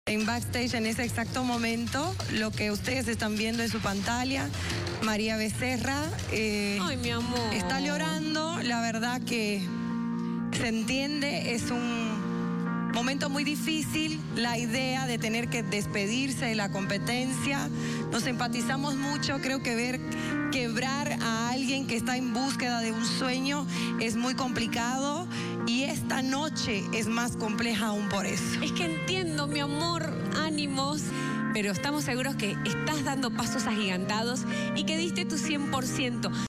Rompe en llanto María Becerra